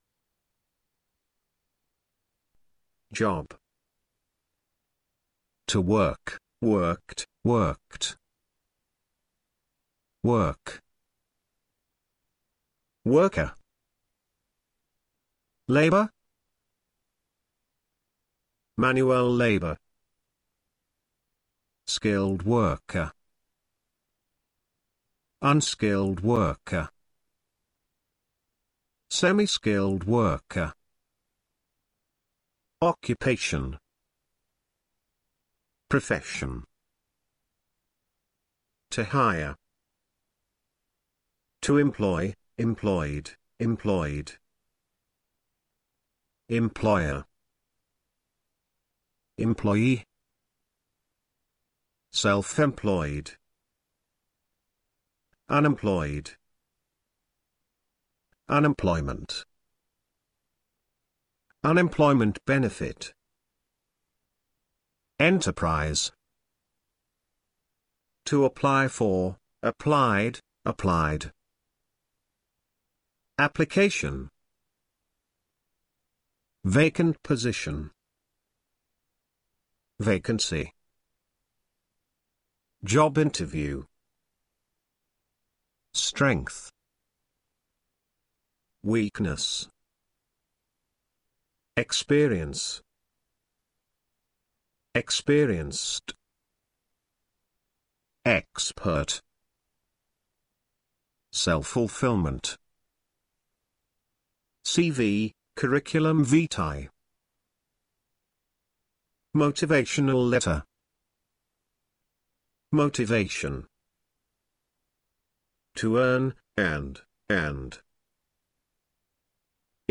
Hallgasd meg a szavak kiejtését az ikonra kattintva.